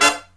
HIGH HIT15-L.wav